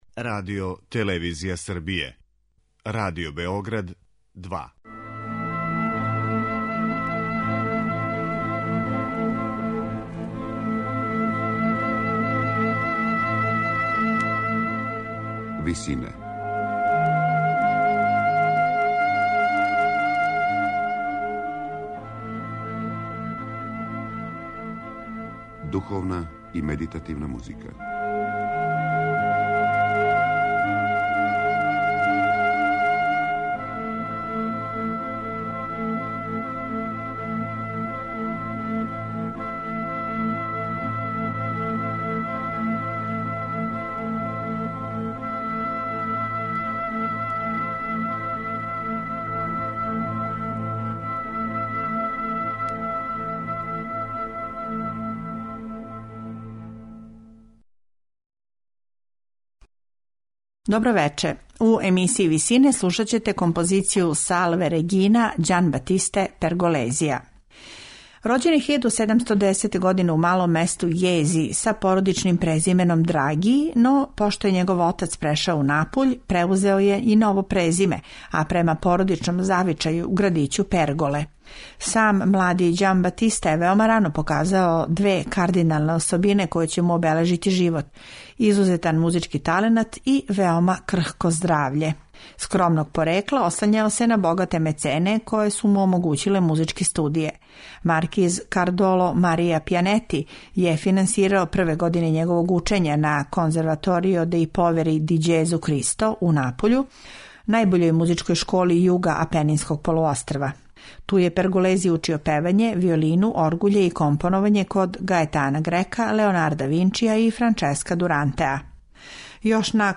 у ВИСИНАМА представљамо медитативне и духовне композиције аутора свих конфесија и епоха.
сопран